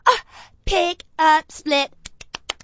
gutterball-3/Gutterball 3/Commentators/Poogie/split_pick_up_song.wav at 893fa999aa1c669c5225bd02df370bcdee4d93ae
split_pick_up_song.wav